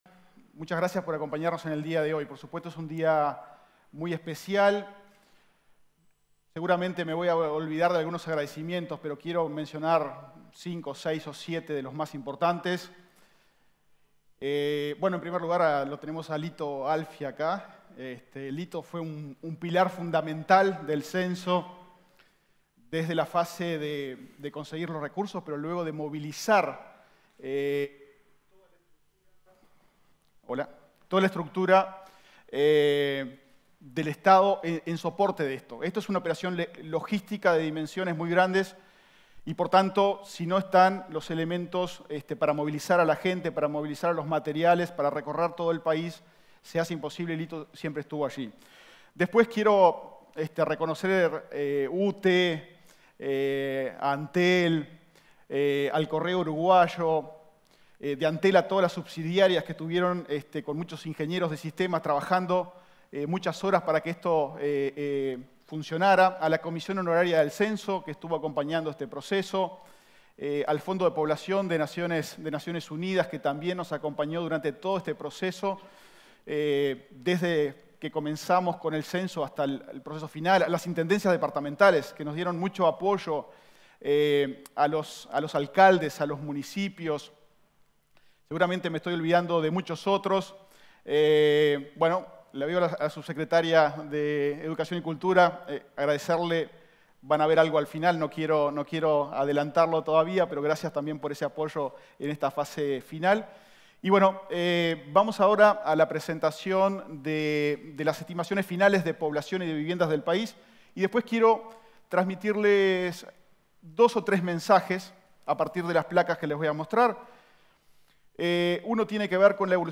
Palabras del director técnico del INE, Diego Aboal
En el marco de la presentación de los resultados finales del Censo 2023, este 10 de diciembre, se expresó el director técnico del Instituto Nacional